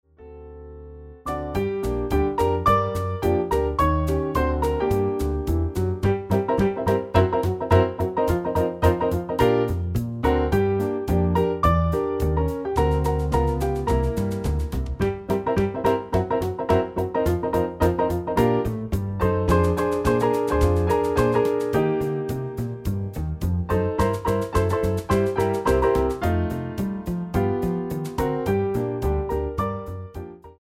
MIDI arrangement for Piano, Bass and Drums
Piano Channel 1
Bass Channel 5
Drums Channel 10